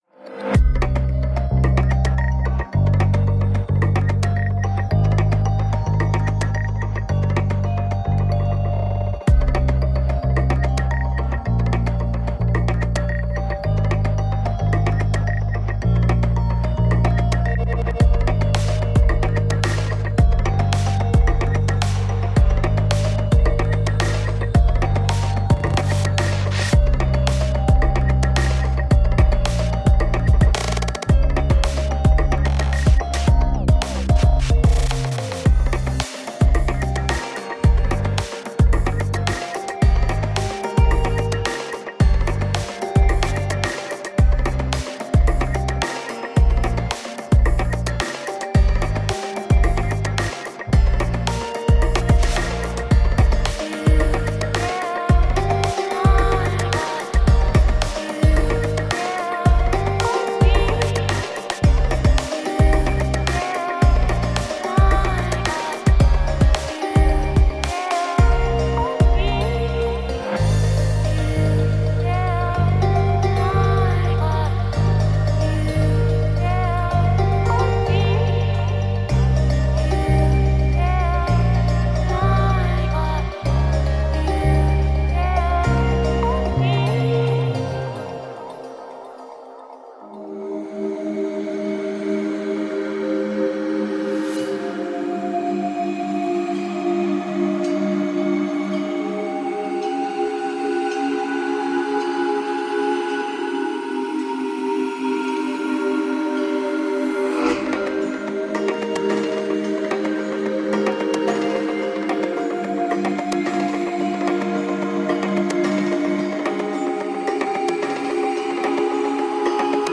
Russian electronica using breaks, atmosphere and chillout.
Tagged as: Electronica, Techno